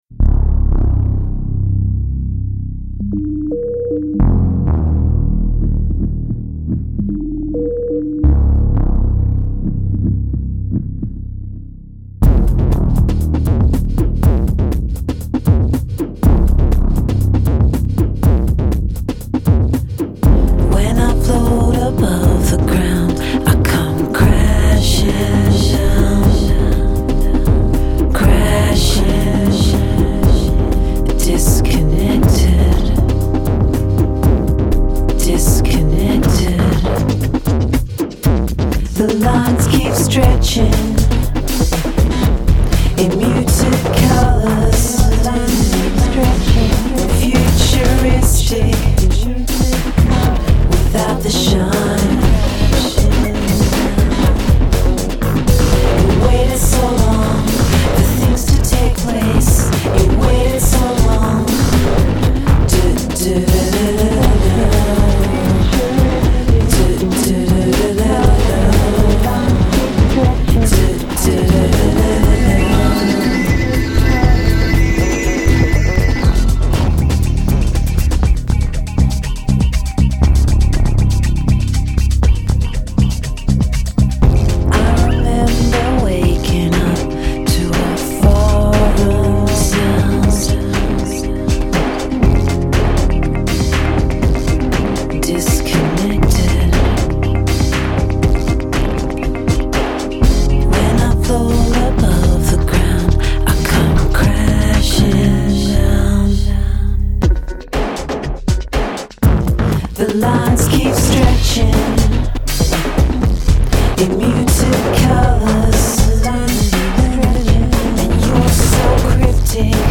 dark electropop